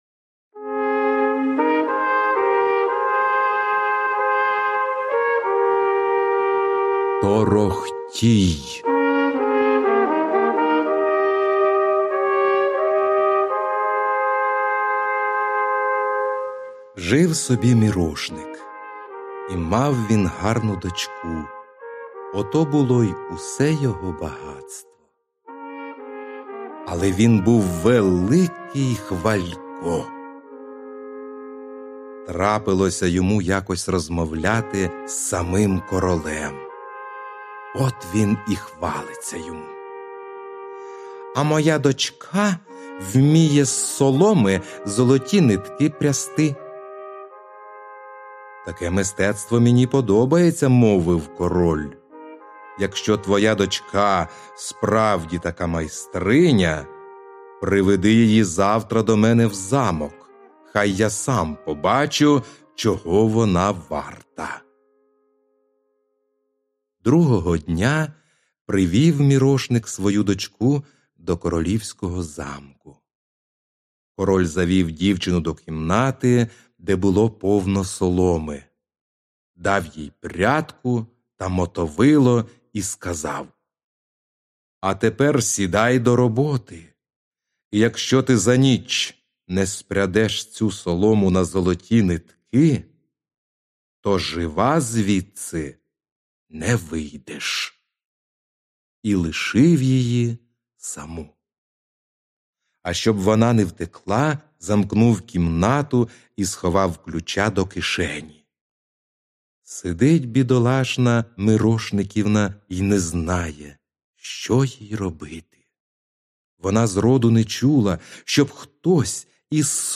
Аудіоказка Торохтій